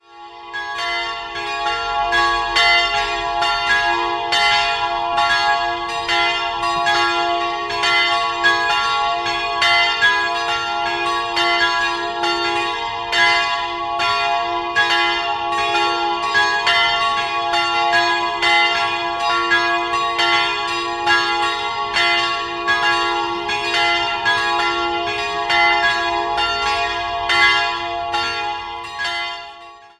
Jahrhunderts. 3-stimmiges TeDeum-Geläute: f''-as''-b'' Die Glocken wurden 1972 in Heidelberg gegossen.